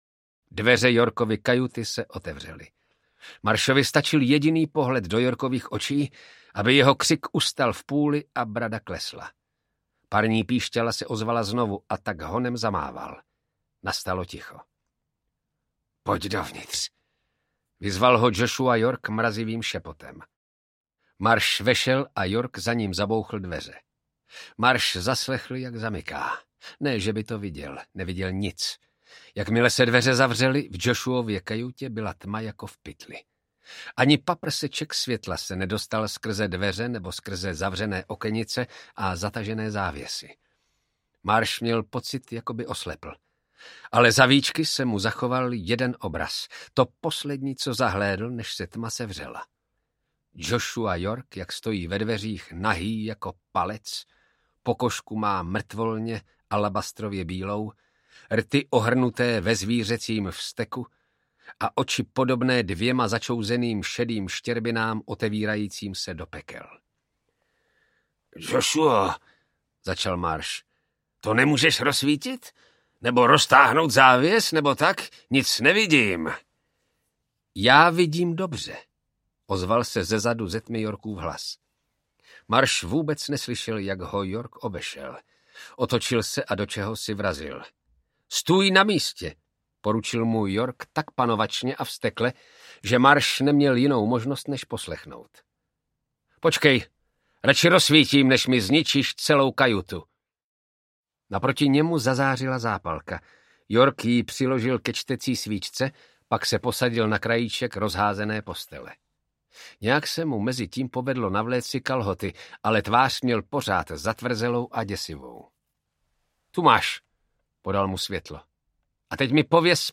Sen Ockerwee audiokniha
Ukázka z knihy
Vyrobilo studio Soundguru.